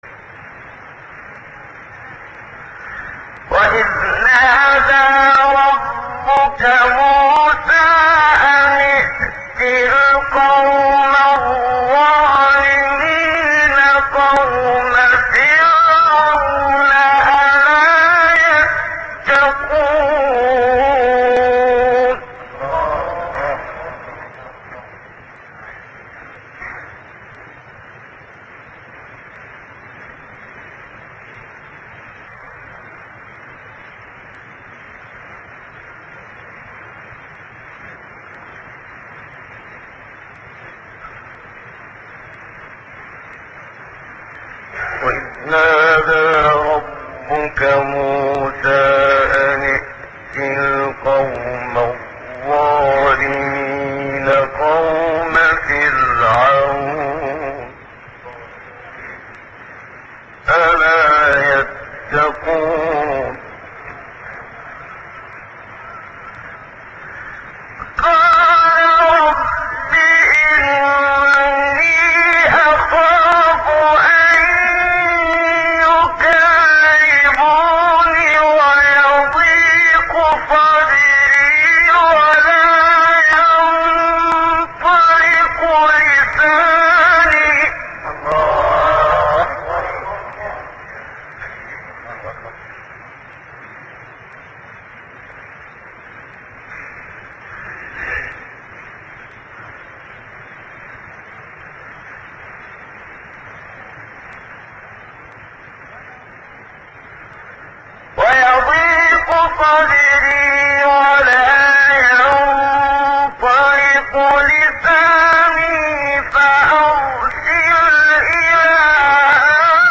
سایت-قرآن-کلام-نورانی-منشاوی-صبا-1.mp3